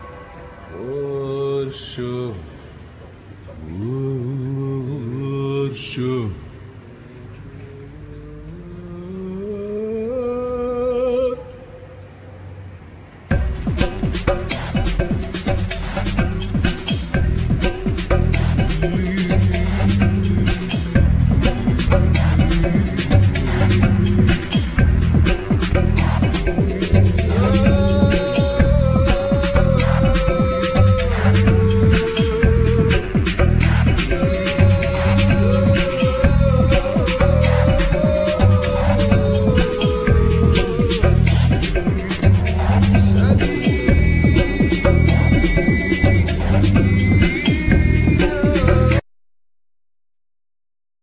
Voice,Samples,Percussion
Bass
Drums
Oceanic sampled guitar
Fire-samples guitar
Mimetic beats
Voices,Rabab,Clarinet,Pandero
Flute